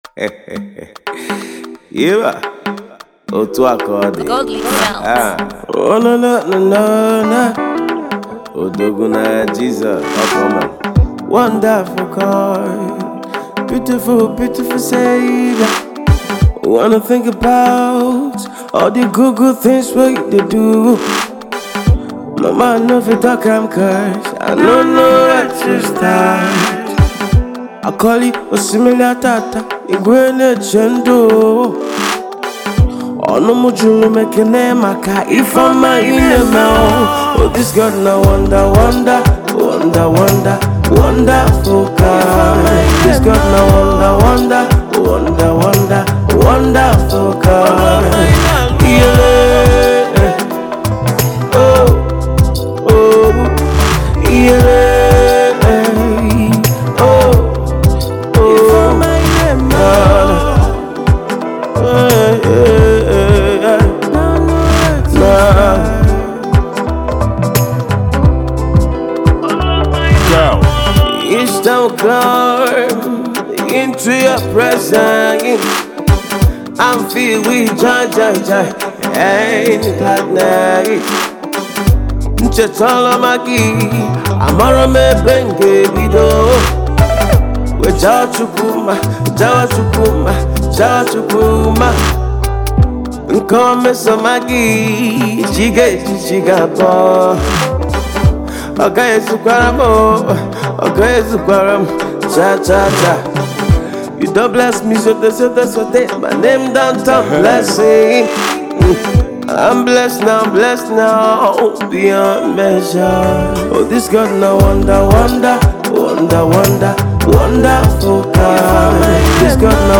gospel R&B soul singer
Afro Hit Song